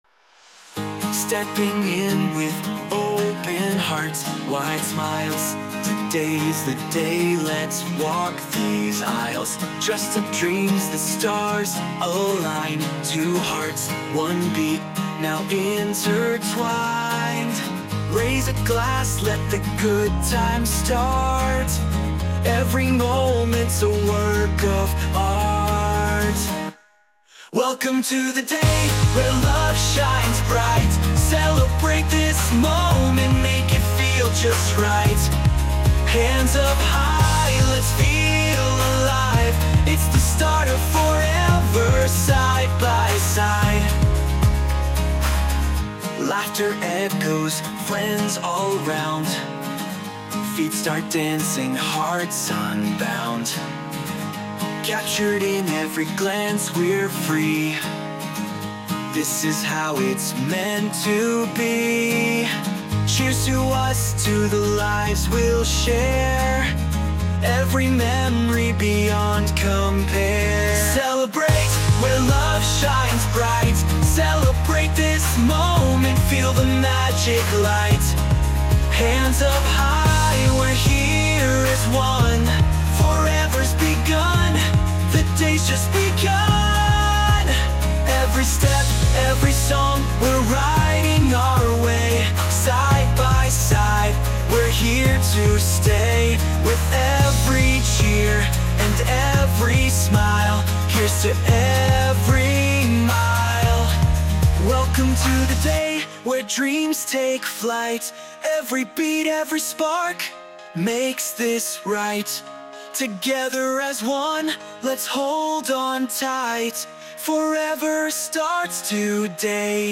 洋楽男性ボーカル著作権フリーBGM ボーカル
著作権フリーオリジナルBGMです。
男性ボーカル（洋楽・英語）曲です。